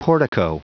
Prononciation du mot portico en anglais (fichier audio)